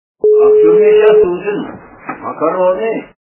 » Звуки » Люди фразы » Из к/ф - А в тюрьме сейчас ужин - макароны
При прослушивании Из к/ф - А в тюрьме сейчас ужин - макароны качество понижено и присутствуют гудки.